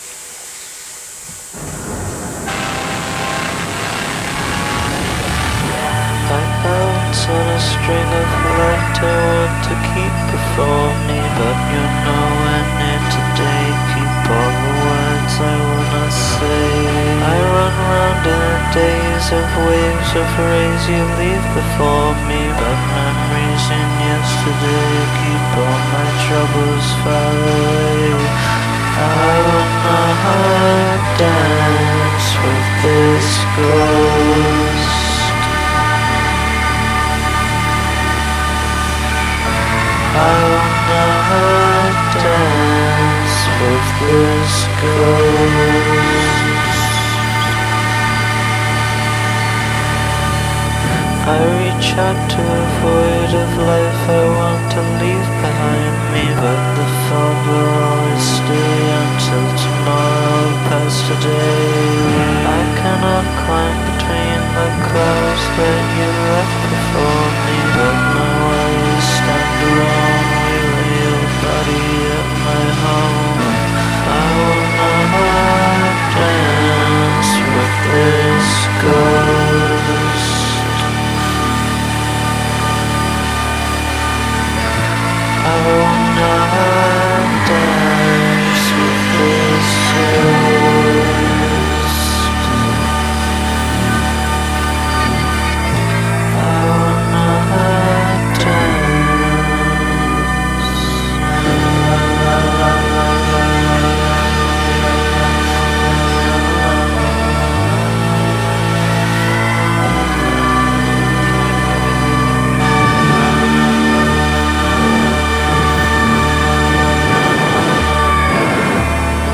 Ethereal Alternative Rock / Post Punk